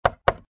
GUI_knock_3.ogg